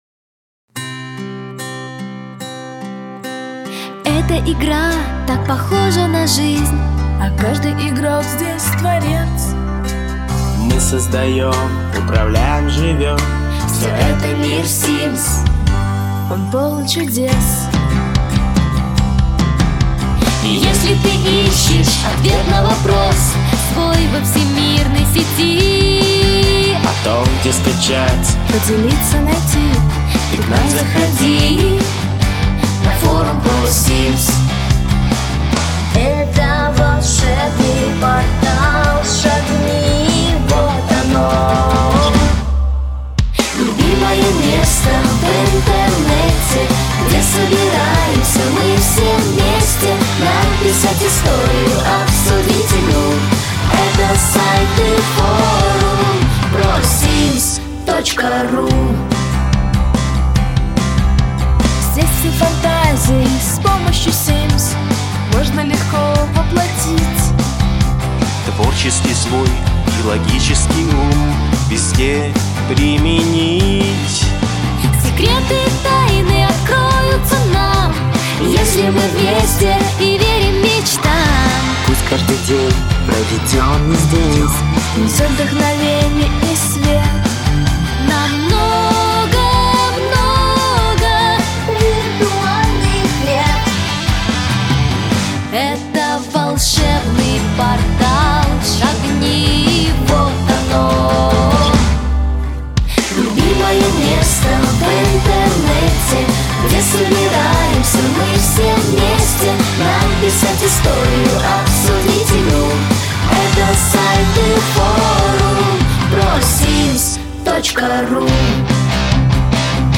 И не какой-нибудь, а записанный профессиональными музыкантами в самой что ни на есть настоящей студии.
Музыкальное произведение буквально потрясло форумчан, ведь оно настолько удачно передало атмосферу портала, настолько задорно и убедительно показало настрой просимовцев, что диву даёшься.
партия гитары и соло
hymn_prosims_studio_chorus_v2.mp3